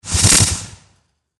Звуки волшебной палочки
Выстрел из палочки злого волшебника